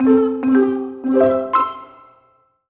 happy_collect_item_04.wav